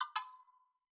Knock Notification 4.wav